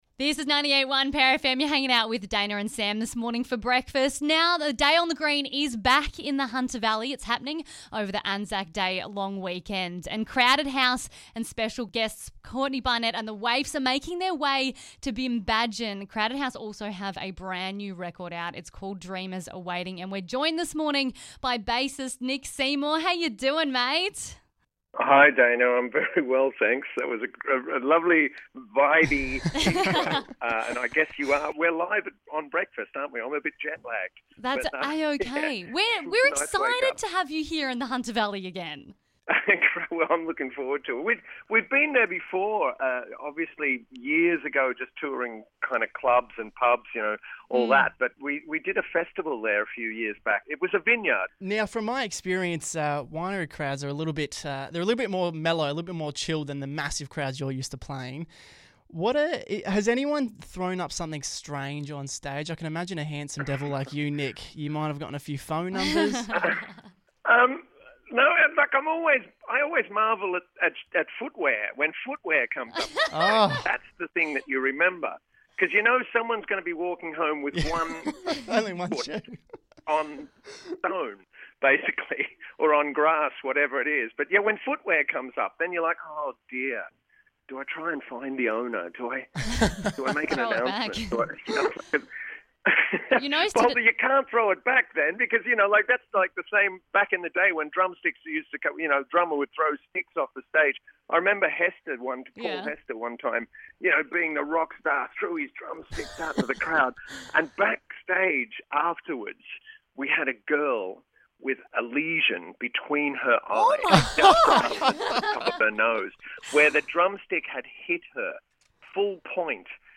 Crowded House are coming to the Hunter Valley at the end of the month for a Day on the Green. Bassist Nick Seymour chatted